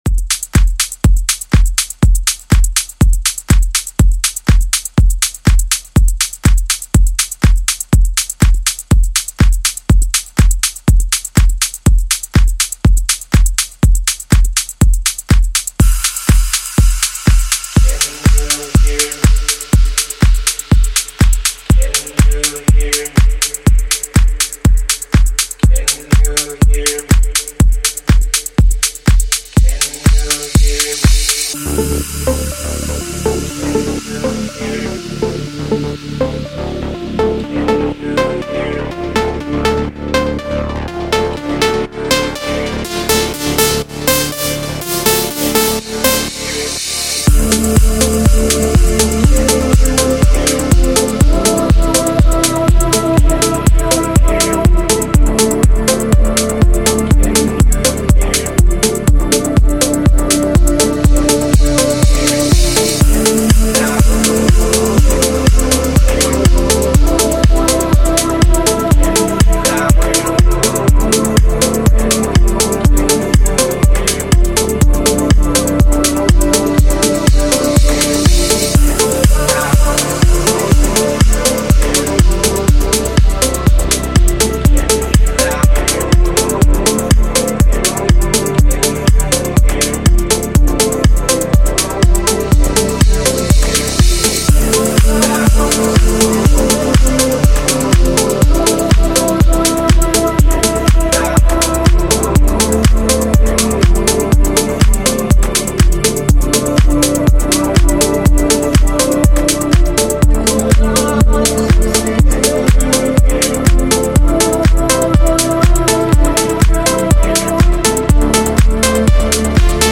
Deep House House